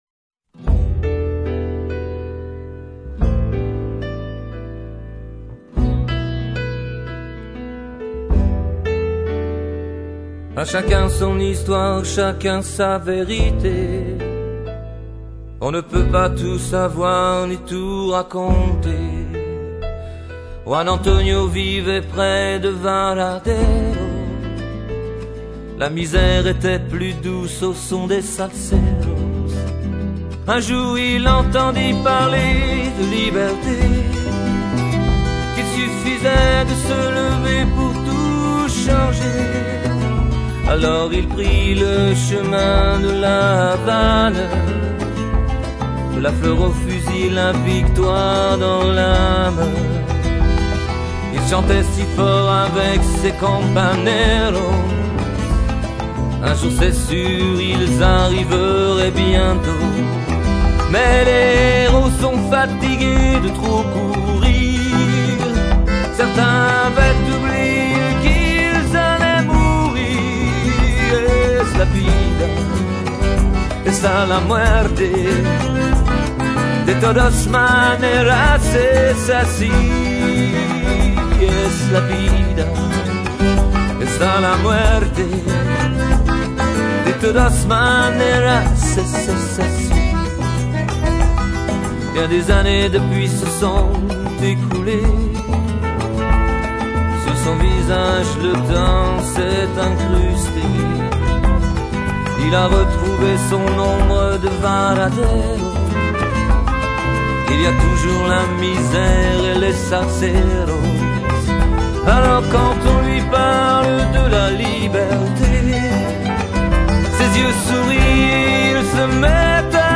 37 - 100 ans - Baryton
guitare
chant